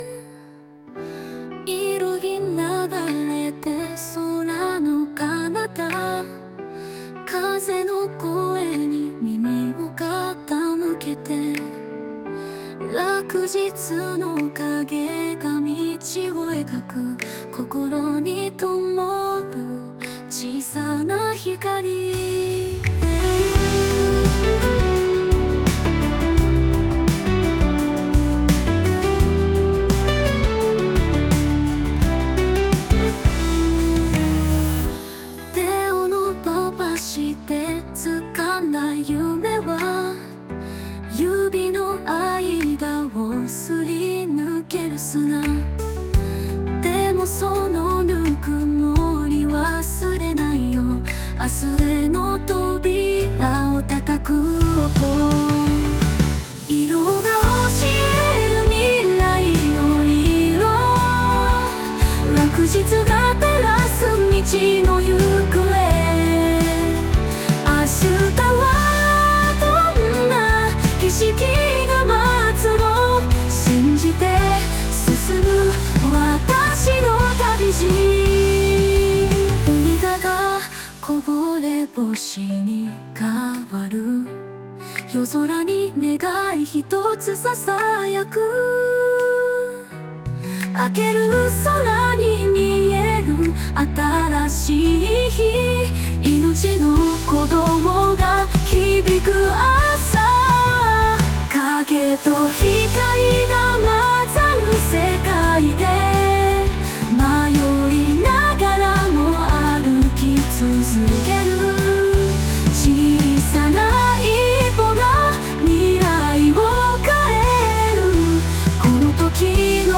Ｒ７年６月　sunoで作った音楽